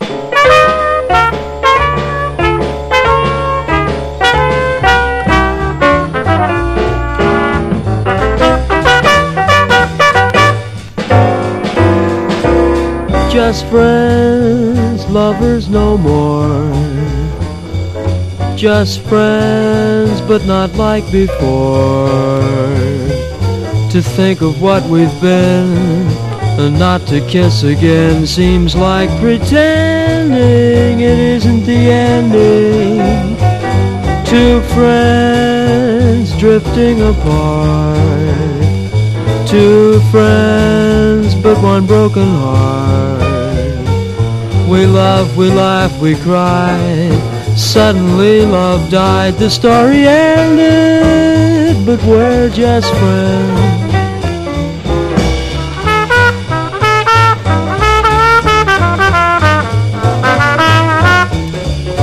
JAZZ / MAIN STREAM / BIG BAND / SWING
ドライヴィンなスウィング・ナンバー